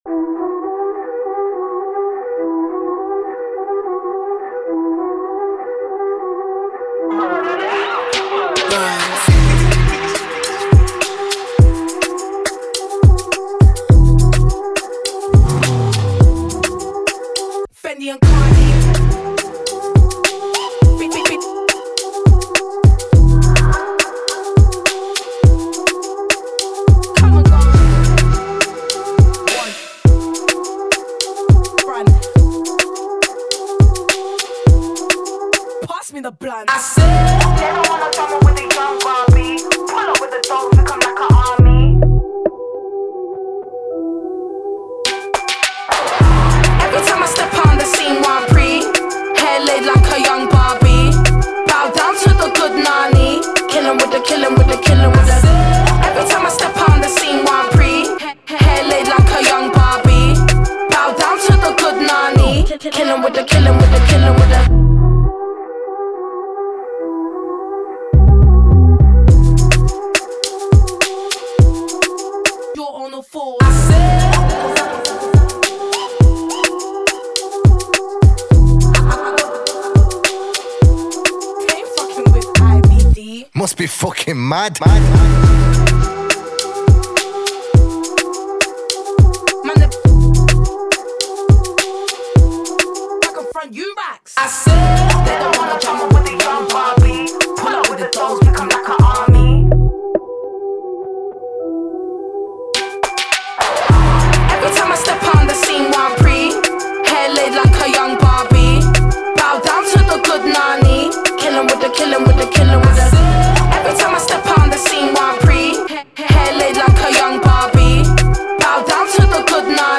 This is the official instrumental
Rap Instrumentals